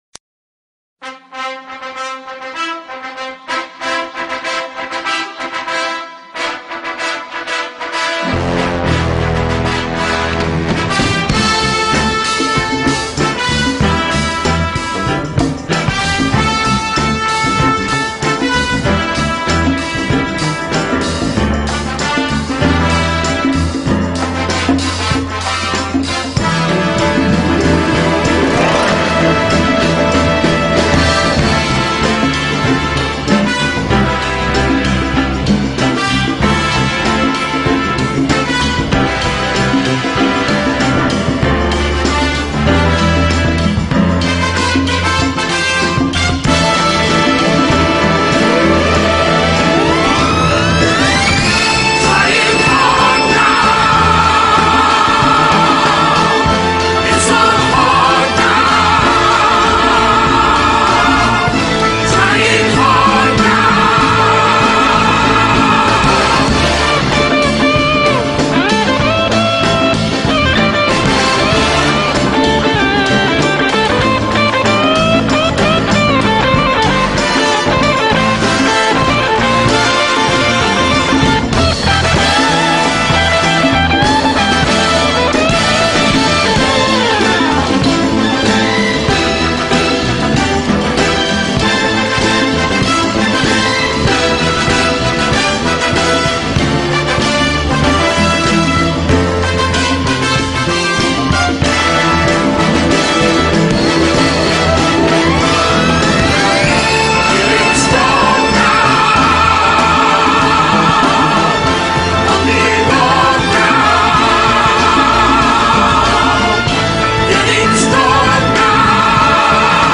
Adam dövmeye giderken motivasyon müziği